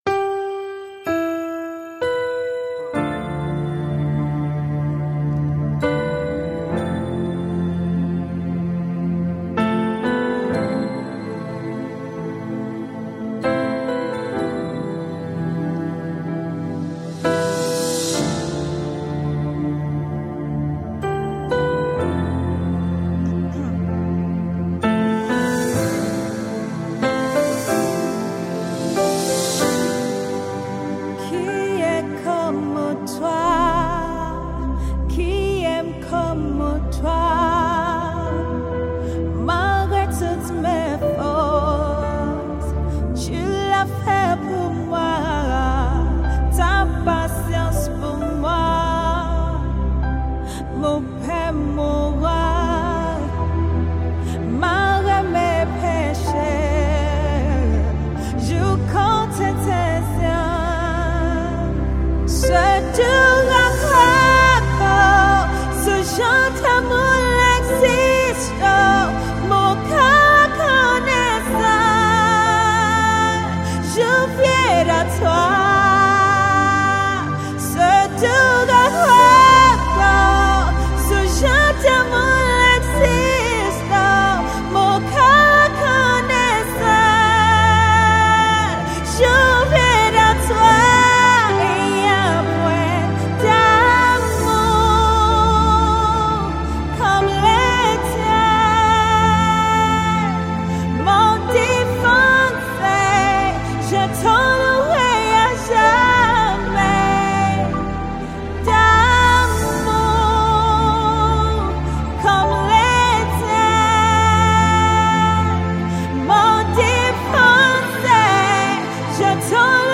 Nigerian gospel singer
her melodic sound and style